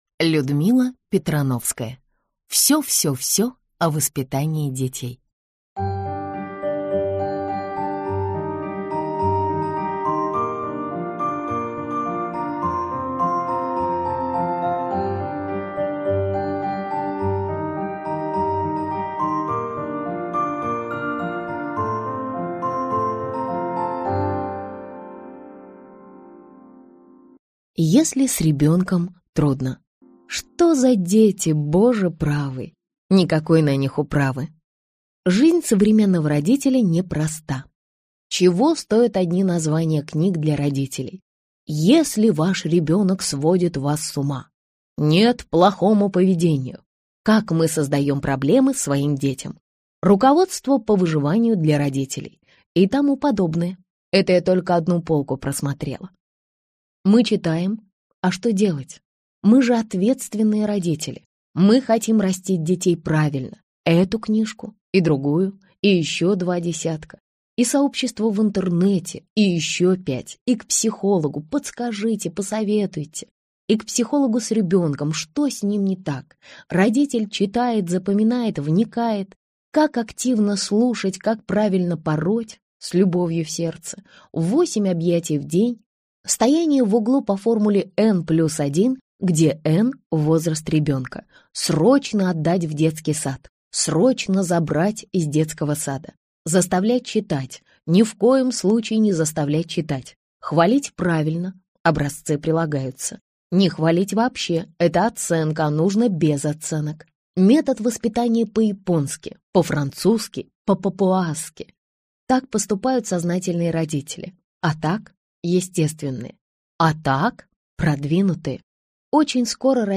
Аудиокнига Всё-всё-всё о воспитании детей | Библиотека аудиокниг